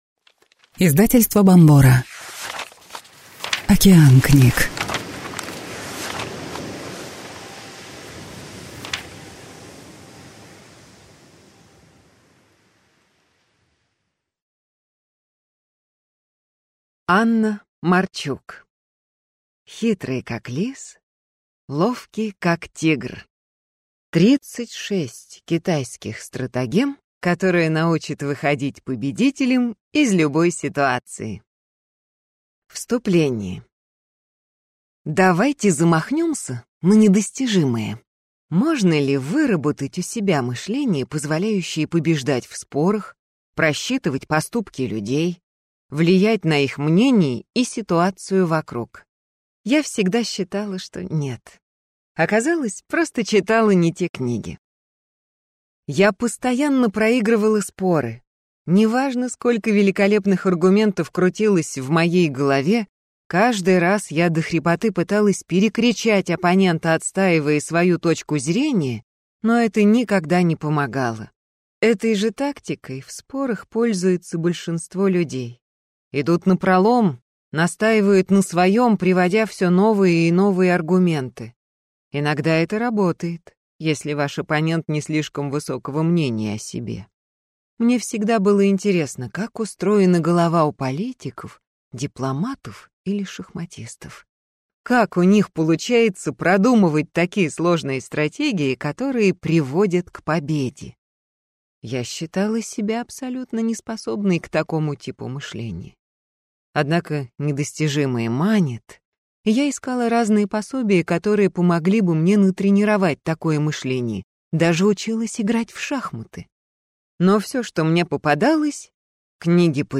Аудиокнига Хитрый, как лис, ловкий, как тигр. 36 китайских стратагем, которые научат выходить победителем из любой ситуации | Библиотека аудиокниг